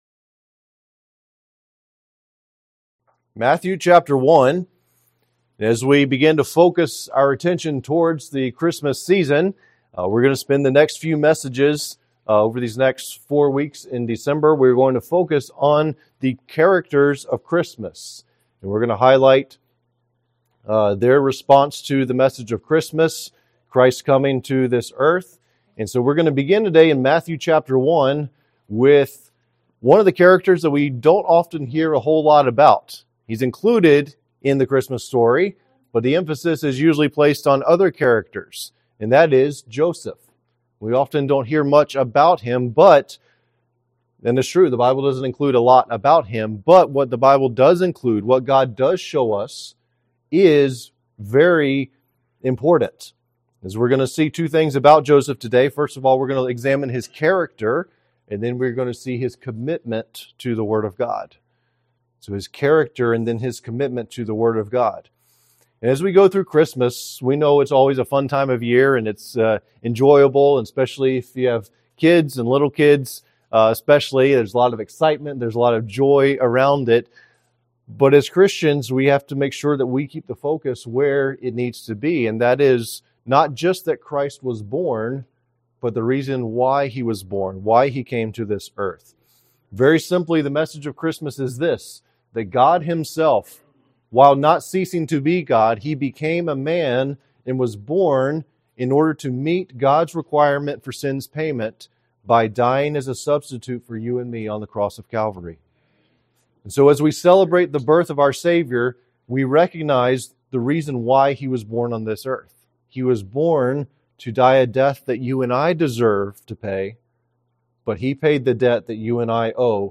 Sermons: The Characters of Christmas: Joseph